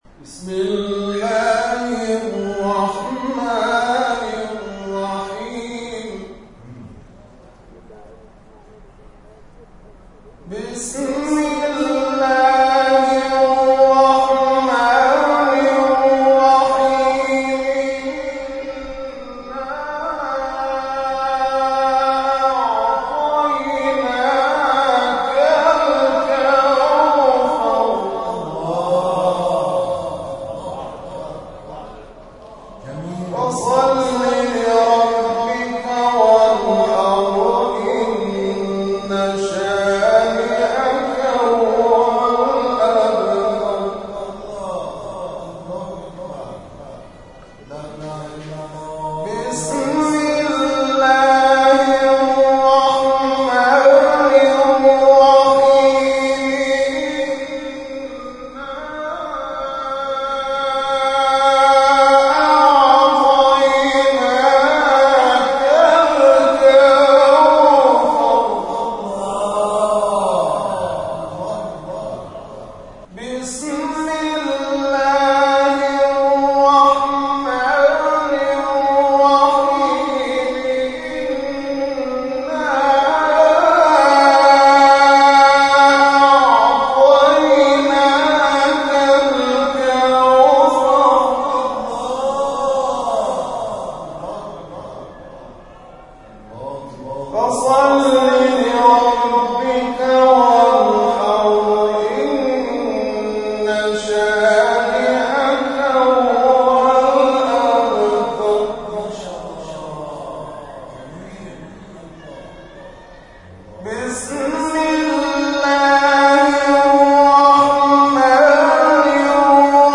آیاتی از سوره مبارکه کوثر و توحید را تلاوت کرد و دعای ختم جلسه را خواند که در ادامه ارائه می‌شود.
این جلسه قرآن، در ماه مبارک رمضان، هر شب از ساعت 23 تا 1 بامداد در مسجد جامع ابوذر برگزار می‌شود.